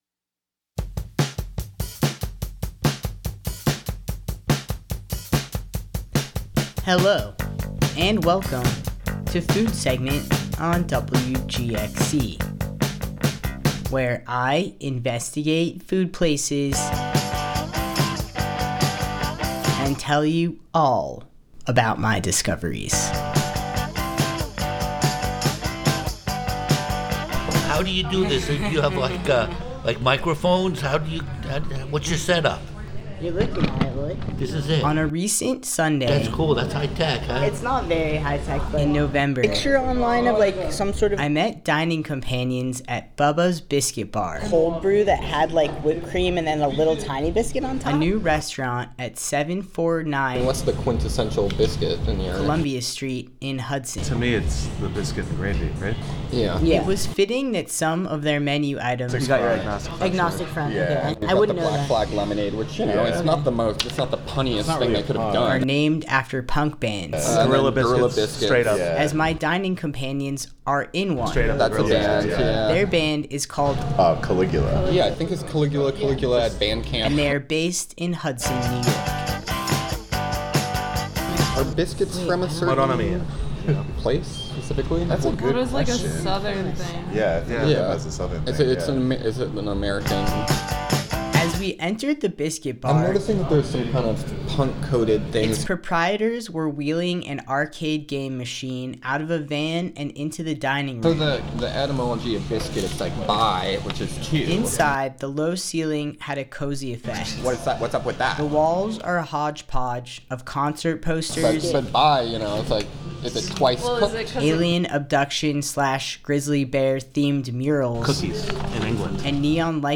This episode features original music by the band:  Samhain (dub) - Caligula